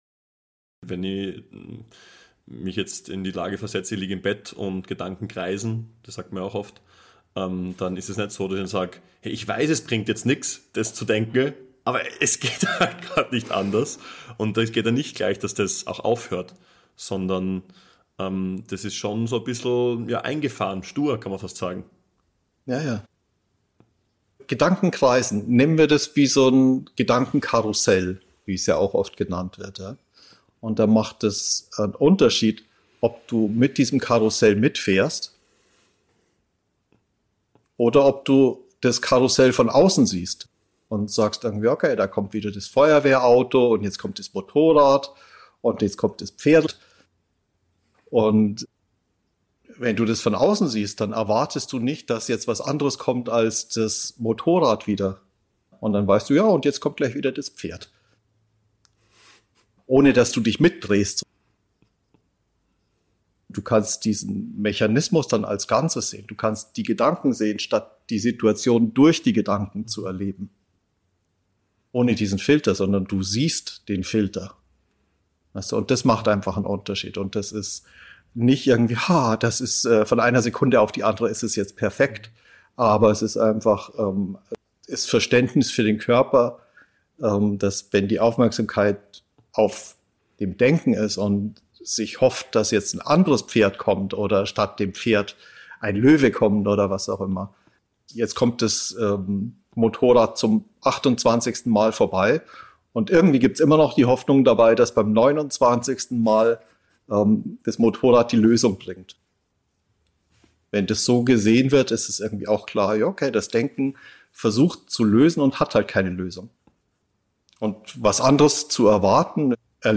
Gedankenkarussell und sinnliche Intelligenz (#2 · Gespräch 1) | Re-Source: Leben als Spielraum ~ Re-Source: Leben als Spielraum Podcast